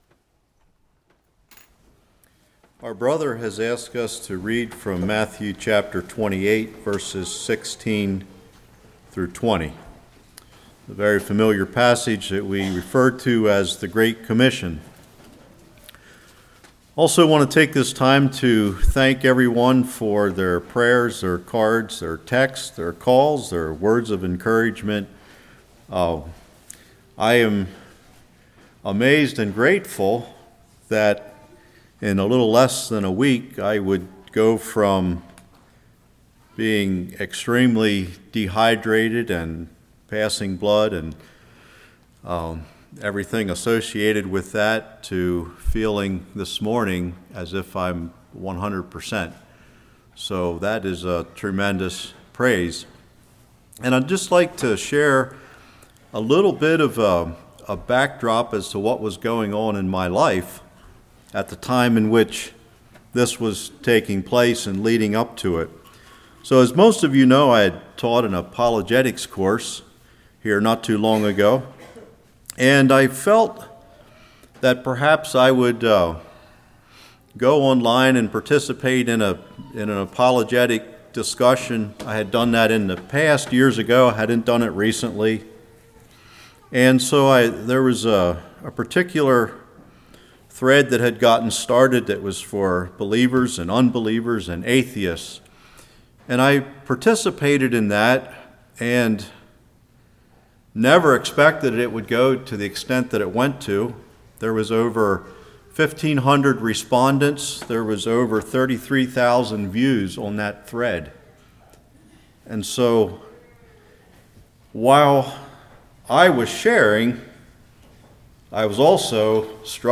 Matthew 28:16-20 Service Type: Morning Missionary imperitive Personal responsibility What is the message?